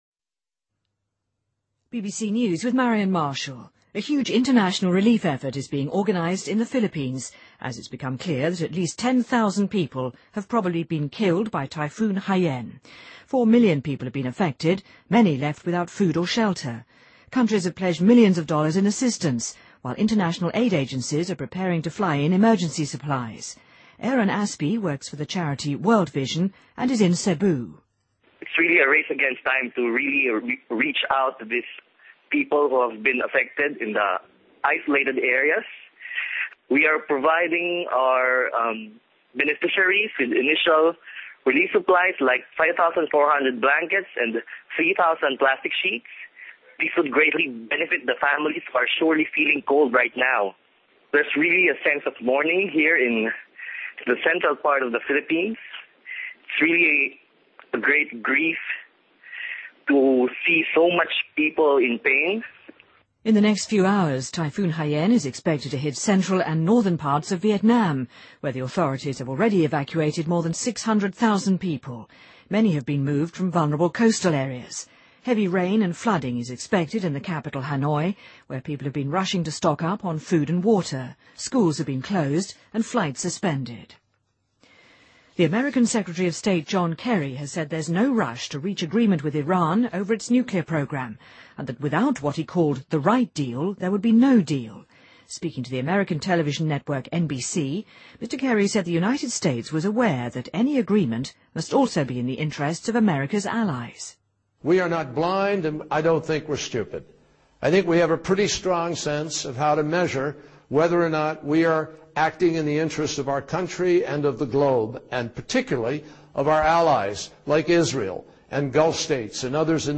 BBC news,欧洲太空局称一颗长达5米重达1000公斤的卫星将坠落地球